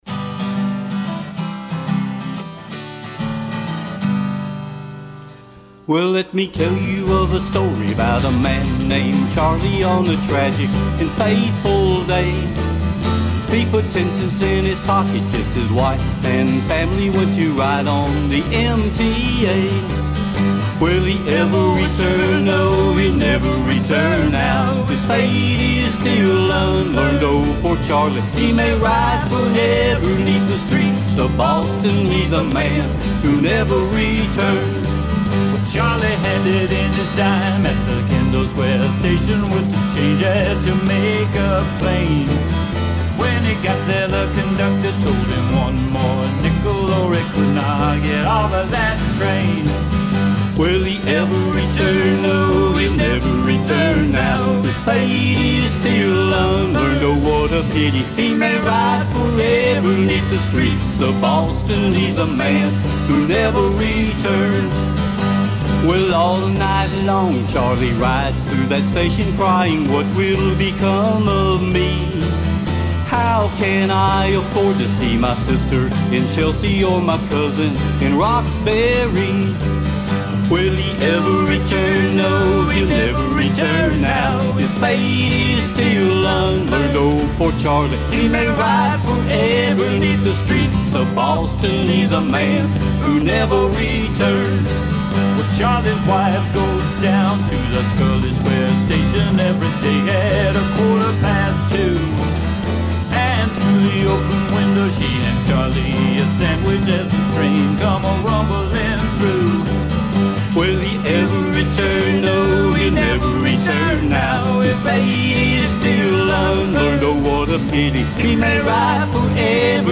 Traditional acoustic music, live and in person.